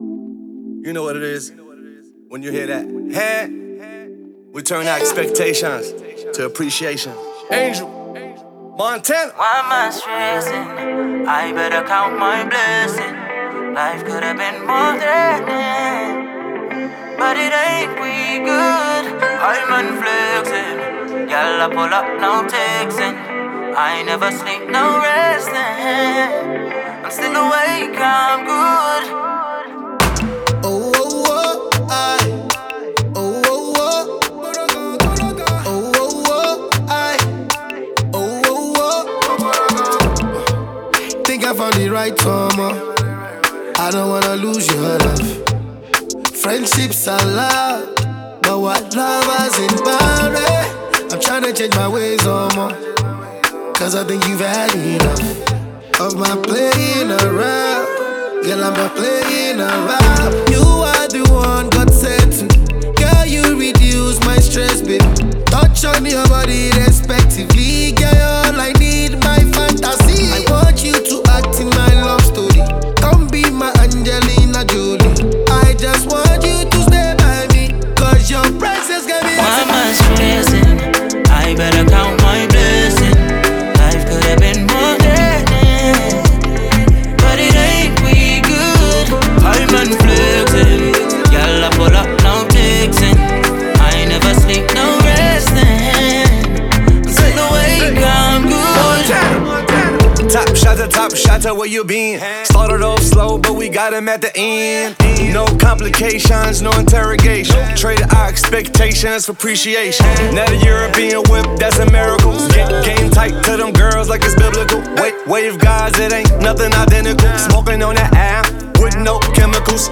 это яркий трек в жанре хип-хоп и афробит